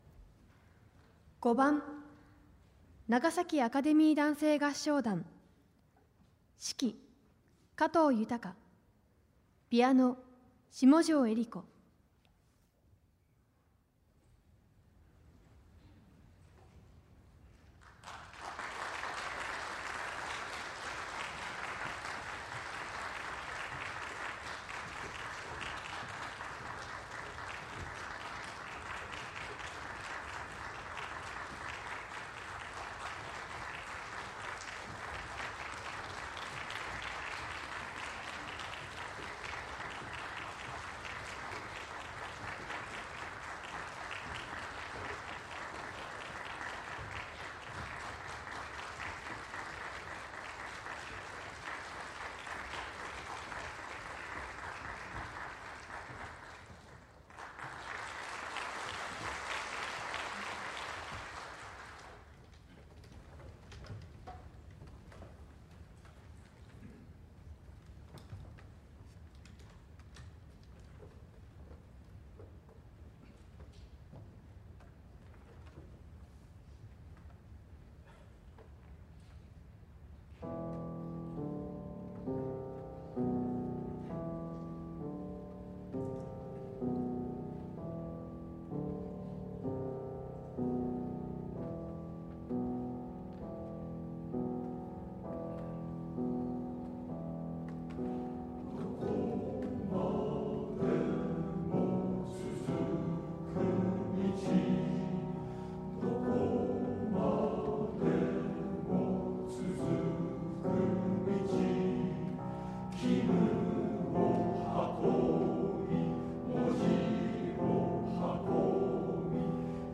2025年7月13日（日） 第28回 長崎県合唱フェスティバル アカ団 金賞を受賞しました！